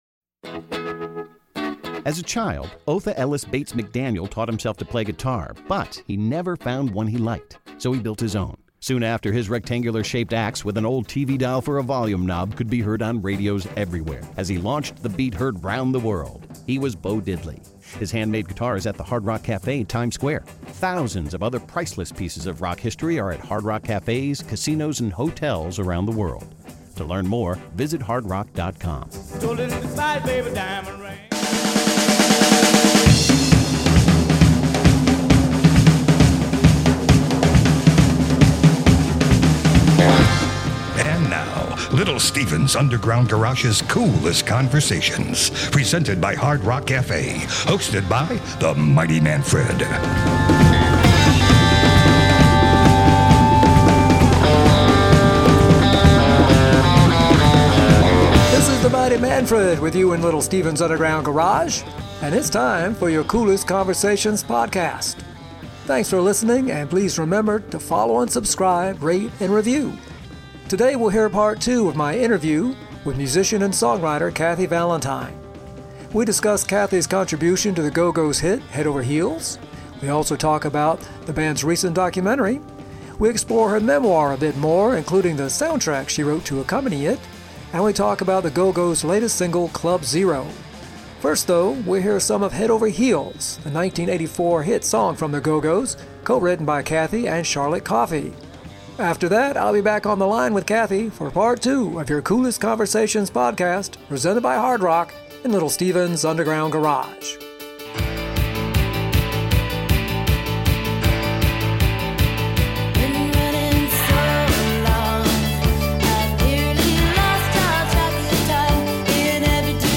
guest is singer, songwriter and member of The Gogo's, Kathy Valentine